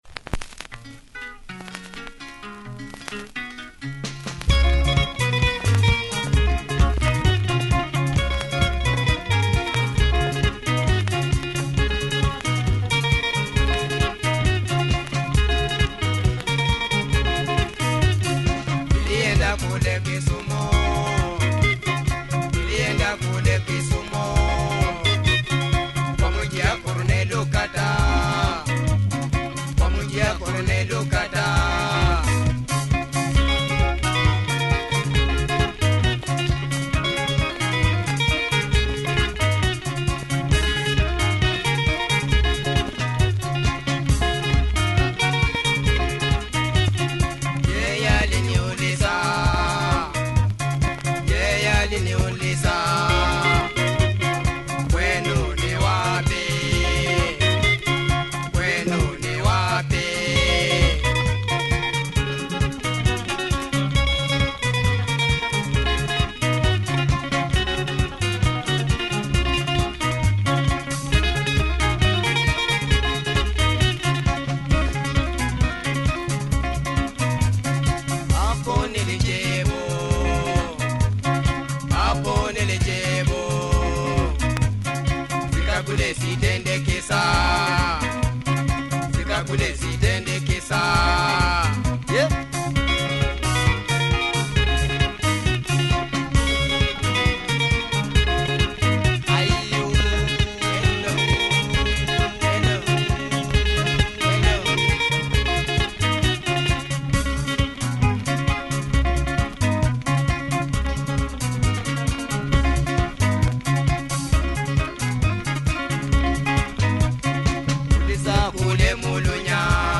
Great drive